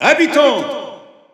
Announcer pronouncing male Villager in French.
Villager_French_Announcer_SSBU.wav